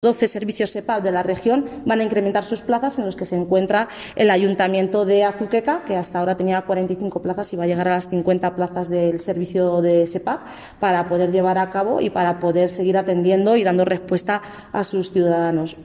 Declaraciones del alcalde José Luis Blanco